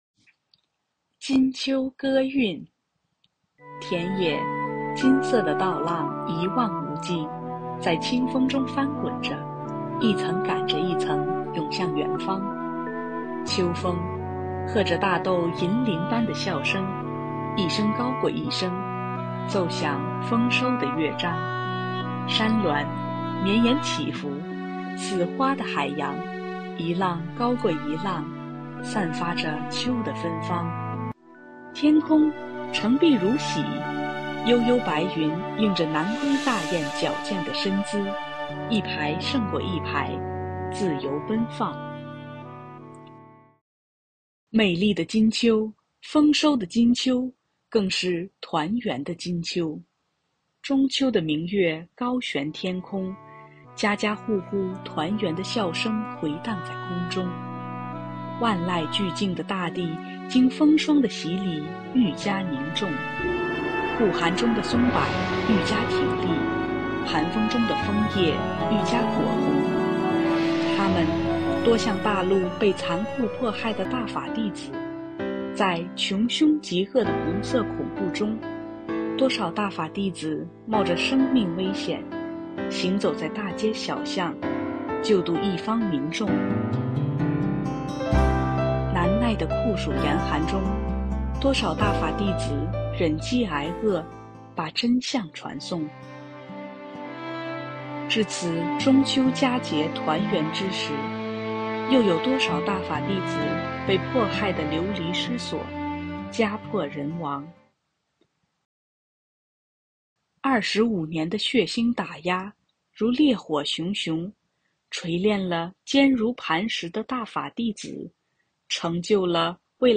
配樂詩朗誦（音頻）：中秋歌韻（MP3）